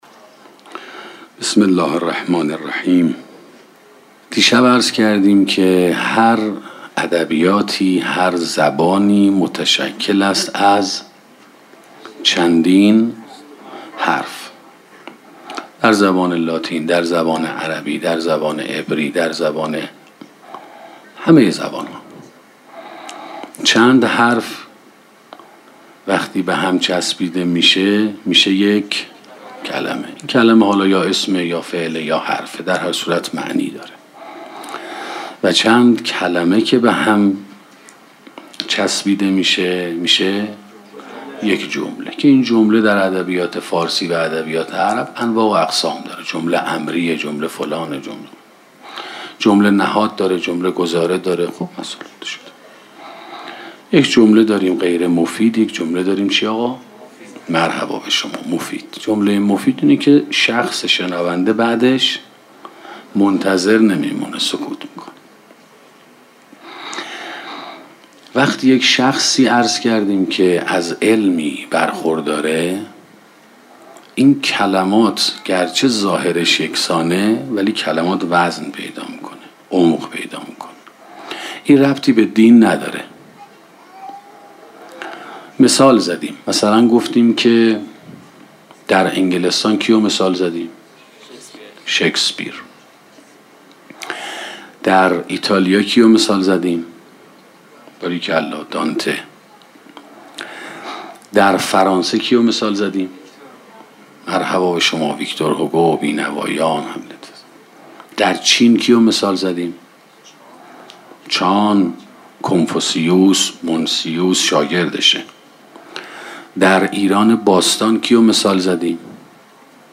سخنرانی سلوک ثاراللهی 3 - موسسه مودت